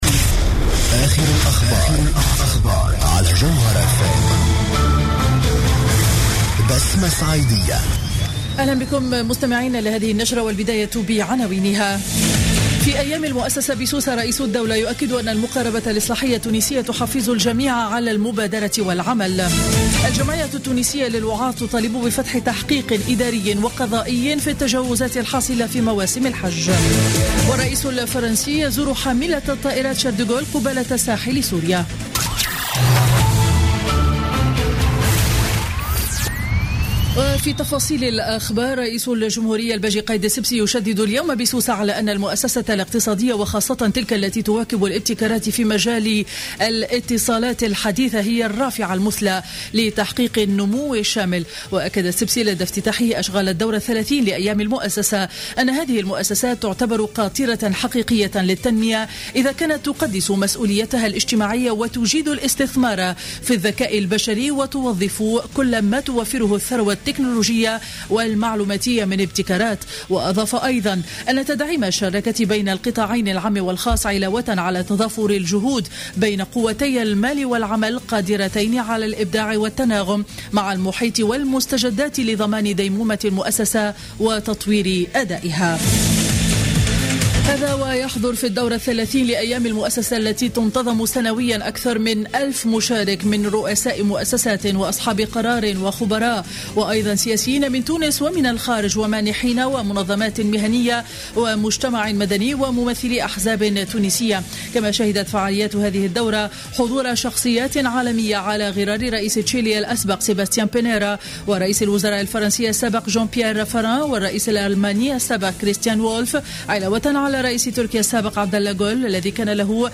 نشرة أخبار منتصف النهار ليوم الجمعة 4 ديسمبر 2015